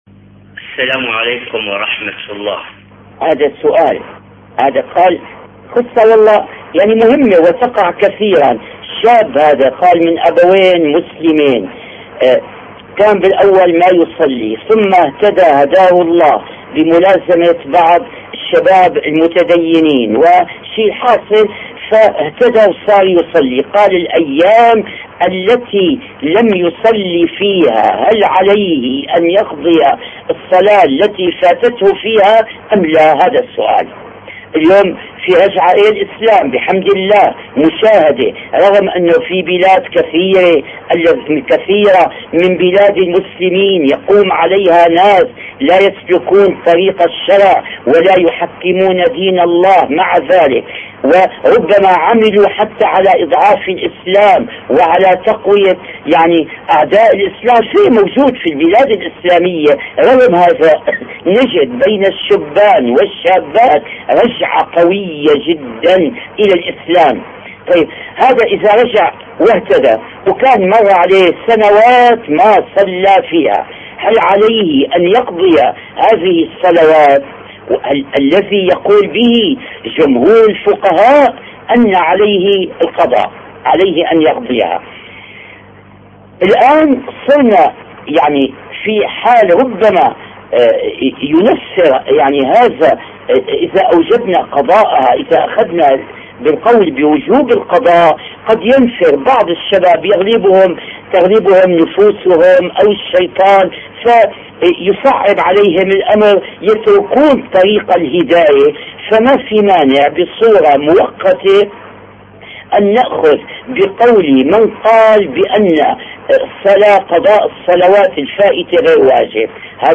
دروس فقهية لفضيلة الشيخ علي الطنطاوي